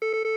defib_saftyOff.ogg